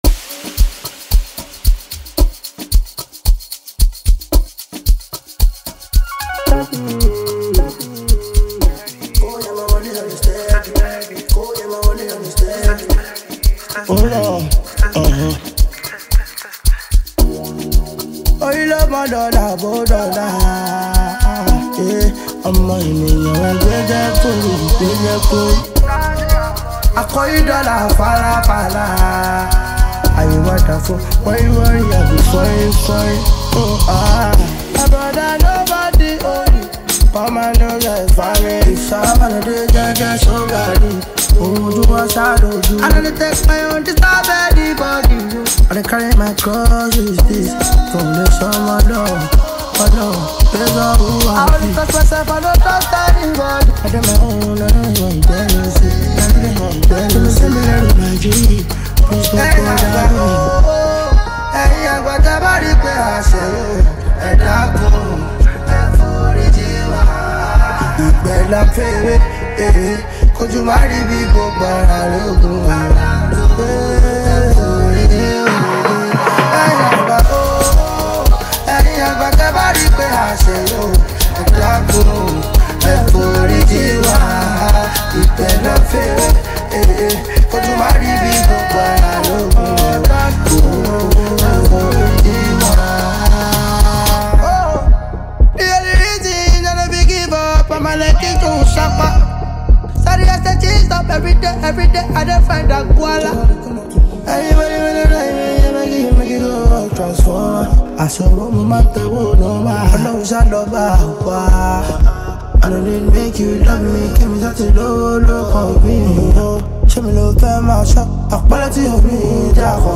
Nigerian singer-songwriter
a reflective yet uplifting record